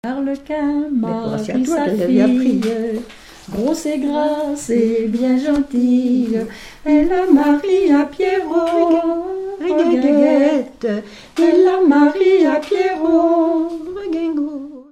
en duo
Genre strophique
Pièce musicale inédite